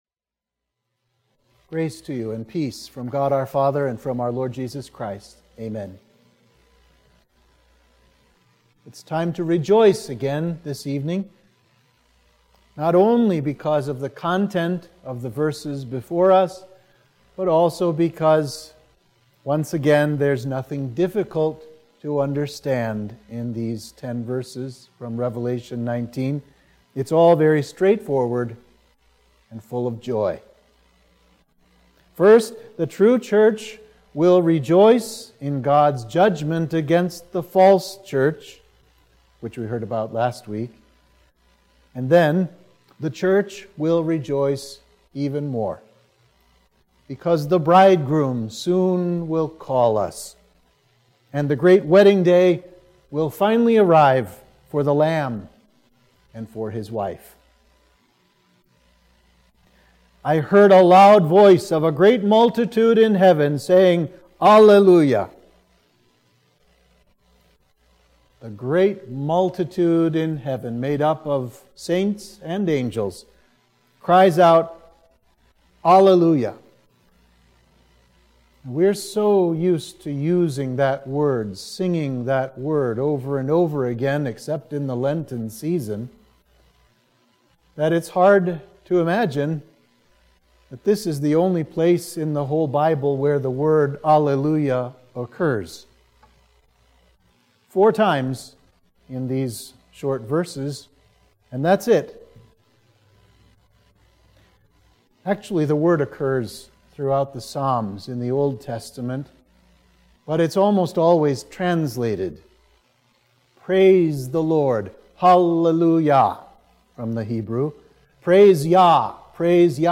Sermon for Midweek of Trinity 15